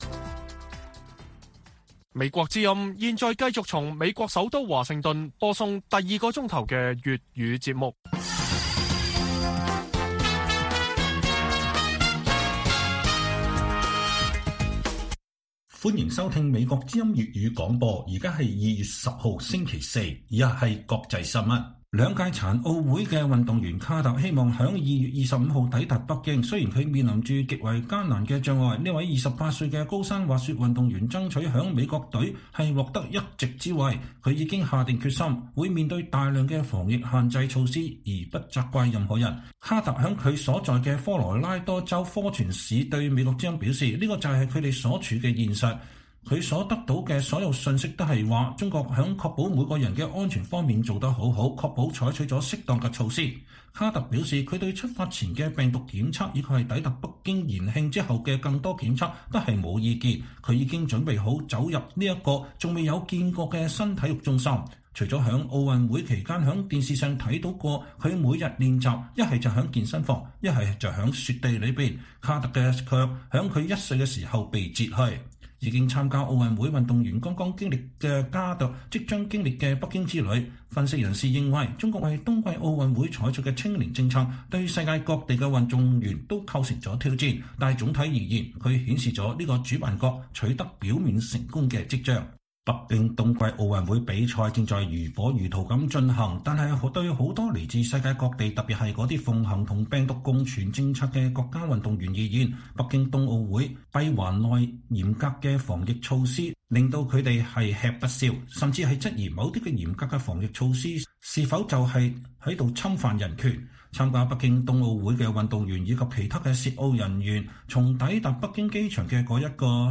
粵語新聞 晚上10-11點 : 貿易專家預期：美中貿易戰會成為持久戰